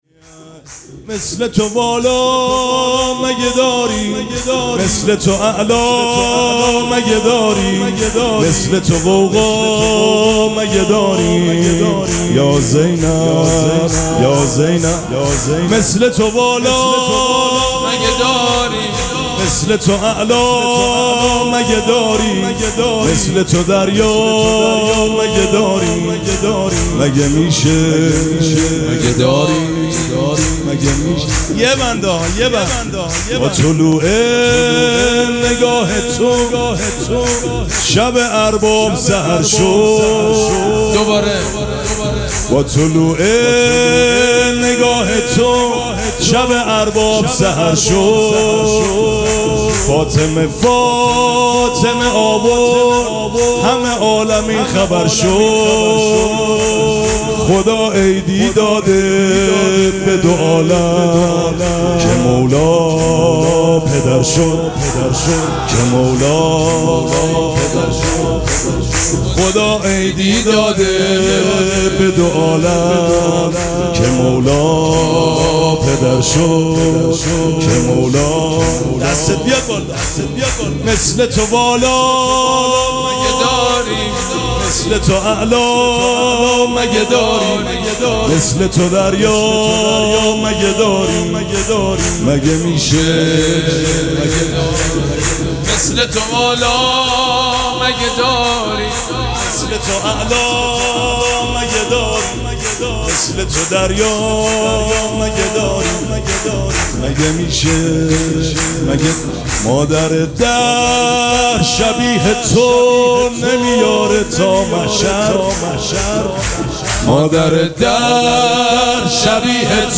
مراسم جشن ولادت حضرت زینب سلام‌الله‌علیها
سرود